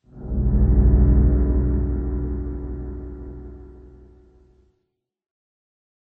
Sound / Minecraft / ambient / cave / cave7.ogg
cave7.ogg